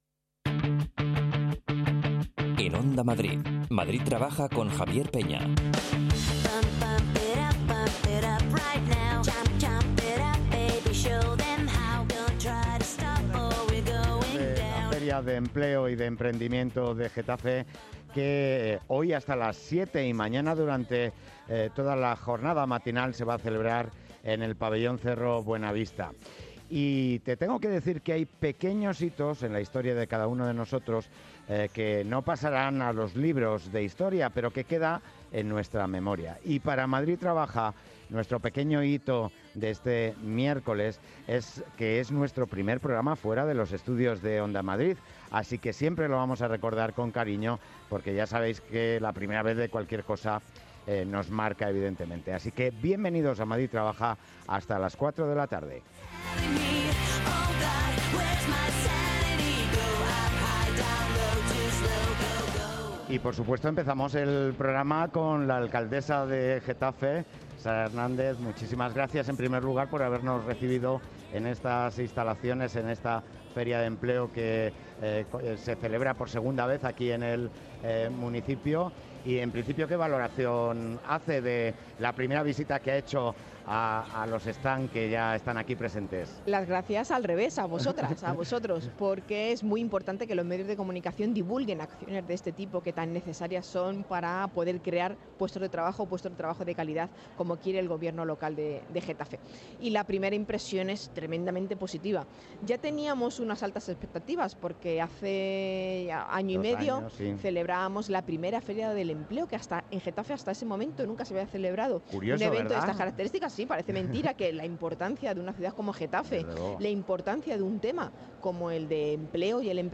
Madrid Trabaja se ha emitido hoy desde el polideportivo Cerro Buenavista donde hoy y mañana se celebra la Feria de Empleo y Emprendimiento de Getafe en la que participa más de una veintena de empresas. Hemos arrancado entrevistando a la alcaldesa de Getafe, Sara Hernández para después charlar con responsables de Recursos Humanos de algunas de las empresas participantes como El Corte Inglés y CostCo.